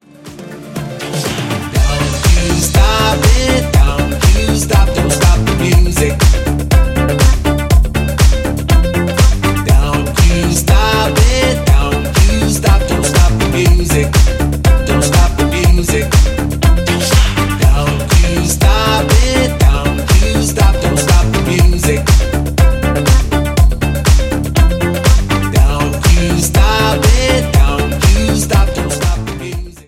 80年代のディスコ、ファンク、ポップス〜に影響を受けたファンキーな楽曲が2枚のヴァイナルに詰まったスペシャルな内容です！